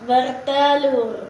Suara_Bertelur.ogg